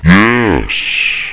1 channel
yesbut.mp3